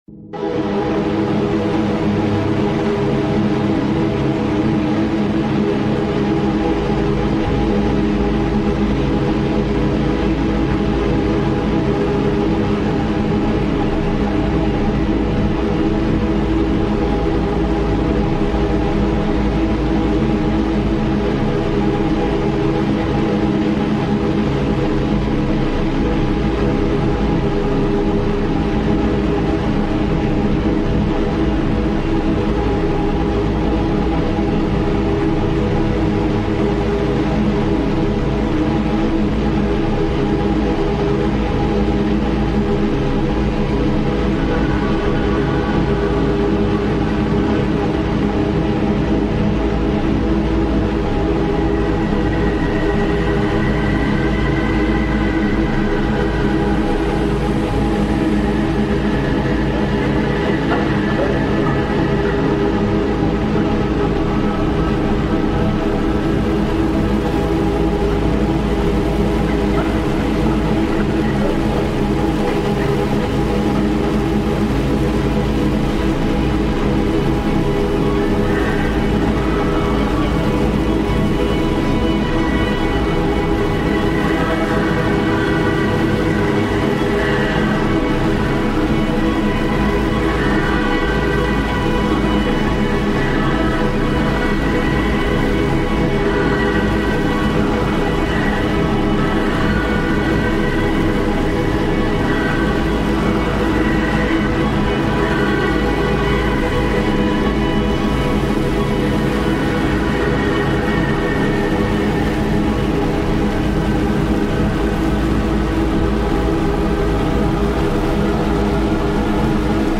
Montenegro reimagined